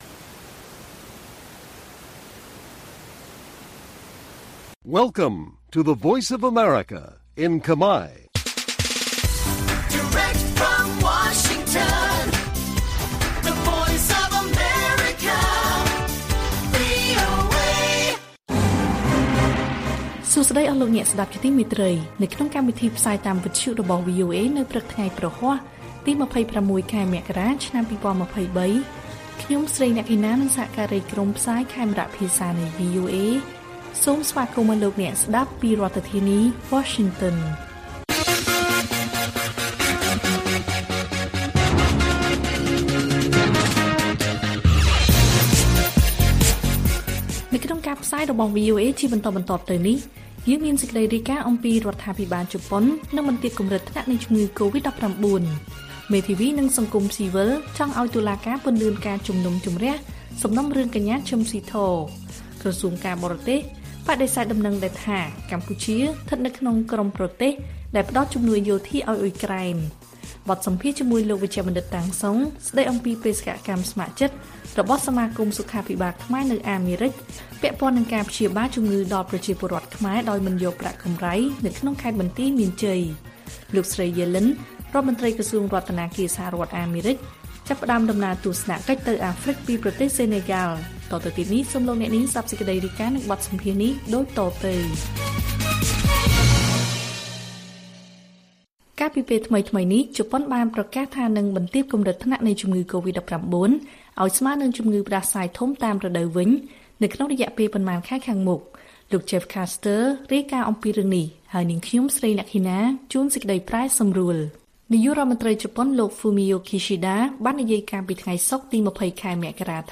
ព័ត៌មានពេលព្រឹក
បទសម្ភាសន៍ VOA